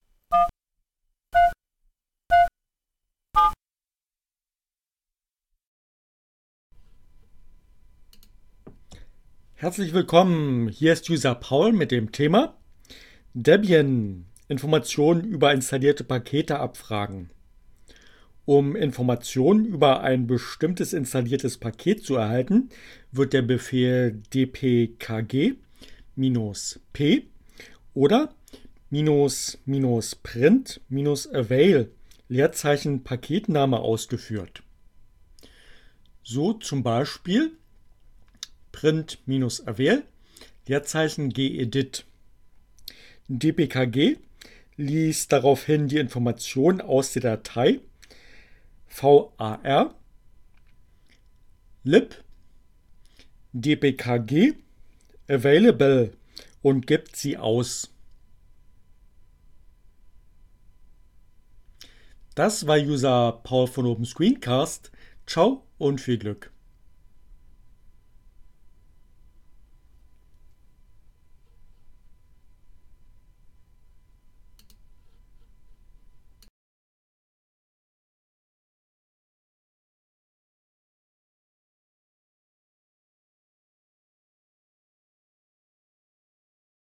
Tags: CC by, Linux, Neueinsteiger, ohne Musik, screencast, Debian, deb, dpkg